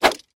Звуки бамбука
Шум вибрации бамбукового стержня